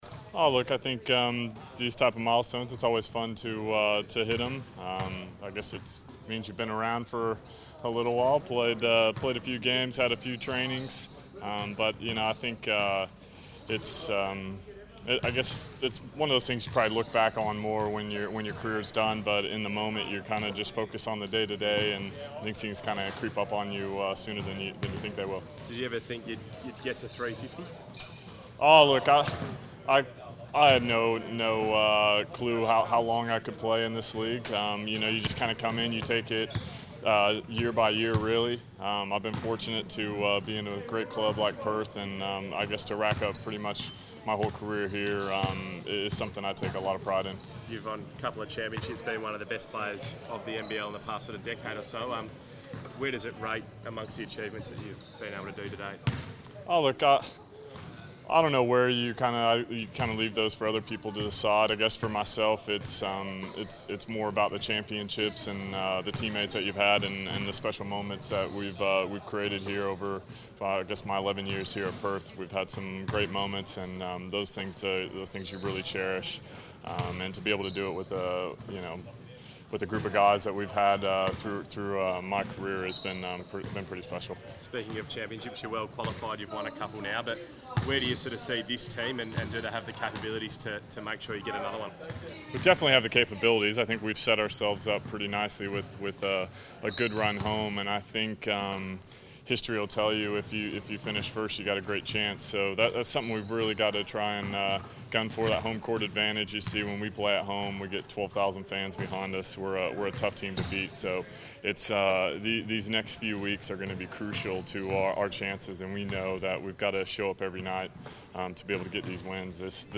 Shawn Redhage press conference - 24 January 2016
Shawn Redhage speaks to the media ahead of his 350th NBL game where the Perth Wildcats take on the Cairns Taipans.